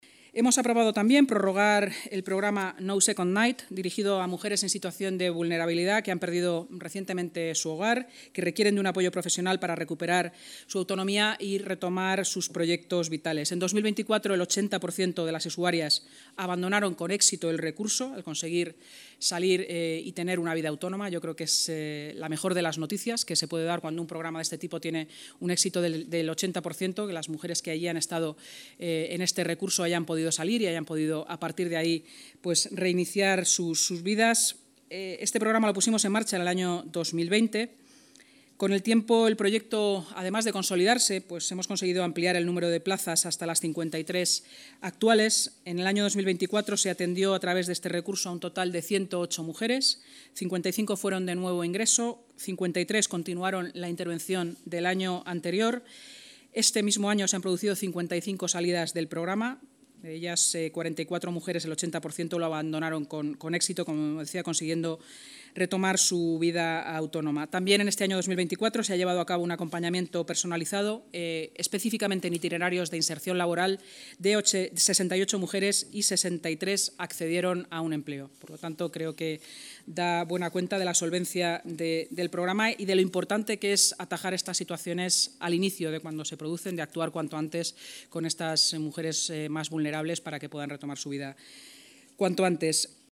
Nueva ventana:vicealcaldesa y portavoz municipal, Inma Sanz: